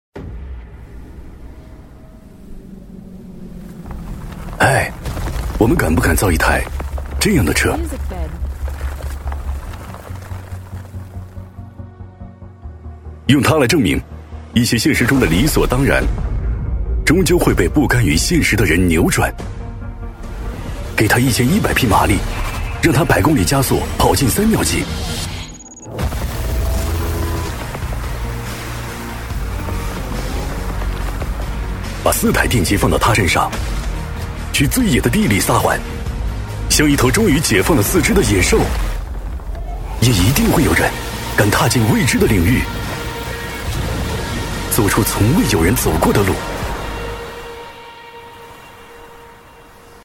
男D6-广告：汽车-比亚迪仰望u8.mp3